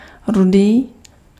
Ääntäminen
France: IPA: [ʁuʒ]